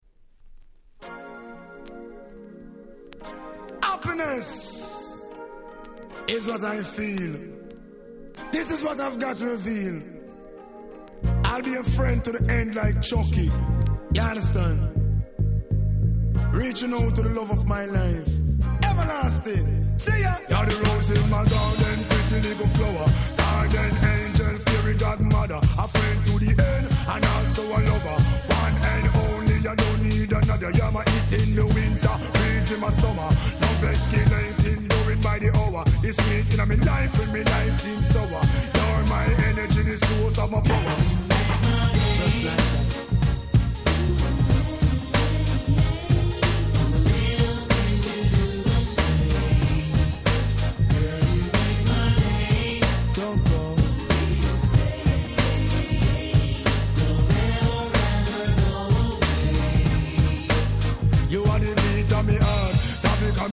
93' Nice Ragga Hip Hop !!
サビのボーカルも気持ち良い、非常にSmoothな1曲！
聴いていると何だか楽しい気持ちになります(^^)